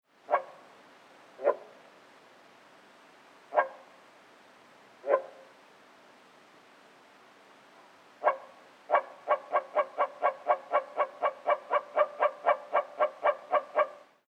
1Moorfrosch, der Schlanke
0876_Moorfrosch_Rufreihe_short.mp3